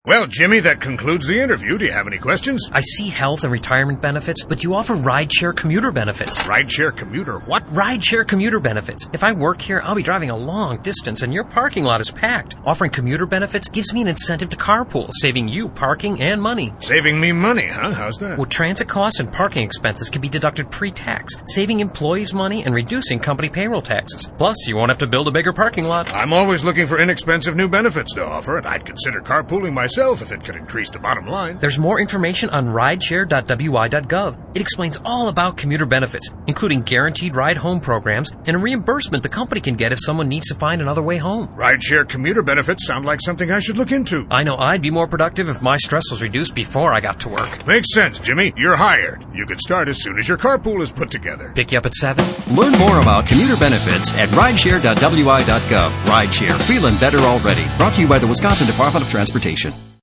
Radio ad - The job interview - Boss hires Jimmy because Jimmy has suggested that commuter options for employees will save employees and the company money.